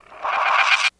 Monster Screech End